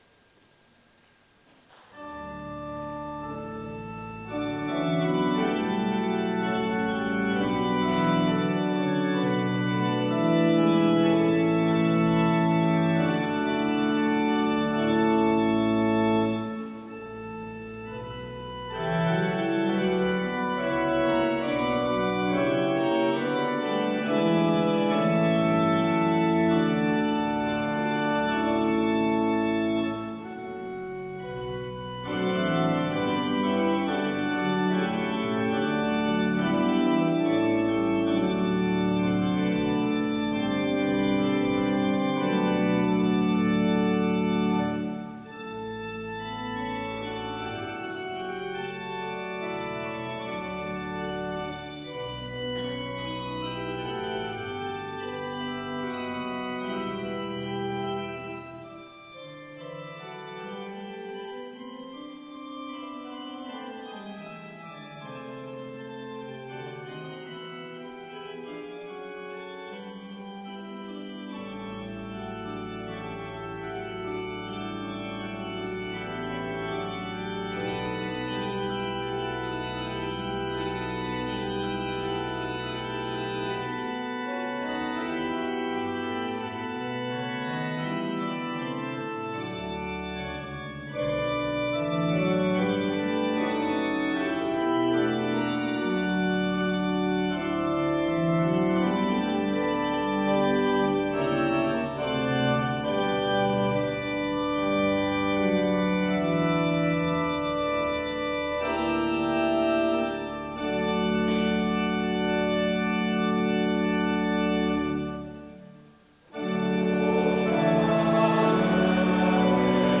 O Heilger Geist, kehr bei uns ein... (LG 151,5-7) Evangelisch-Lutherische St. Johannesgemeinde
Audiomitschnitt unseres Gottesdienstes vom 2. Sonntag nach Trinitatis 2022.